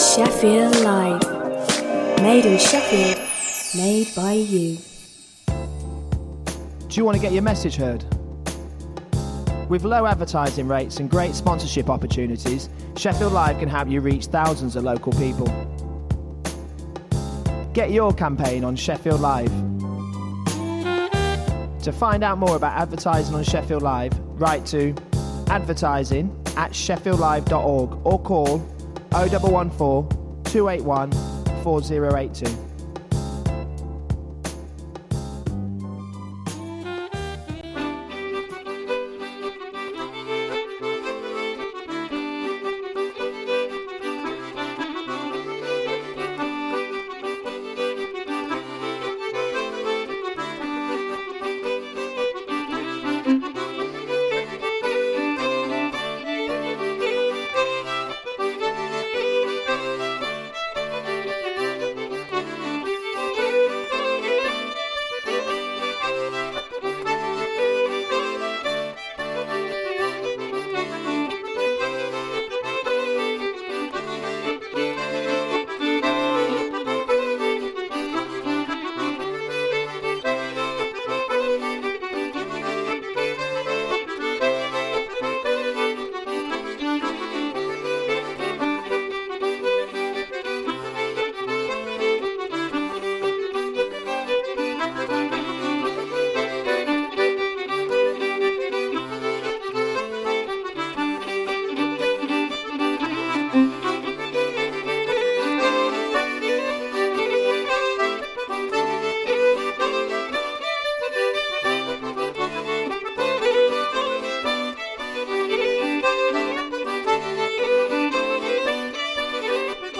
Platinum Plates will re-introduce you to tracks and artist profiles/updates and will re-ignite the light for all the revival Reggae, Rockers, Conscious lyrics, Lovers, Ska and Version fanatics out there.